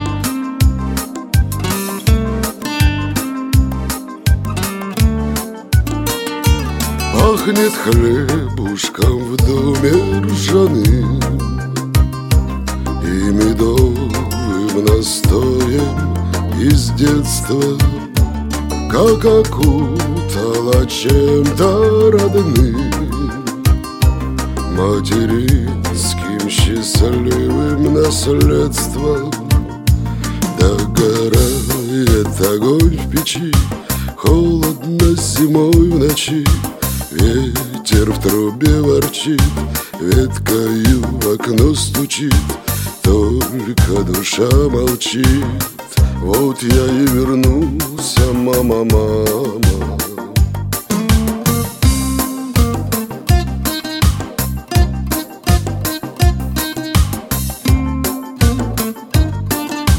Шансон
мелодичным и хриплым голосом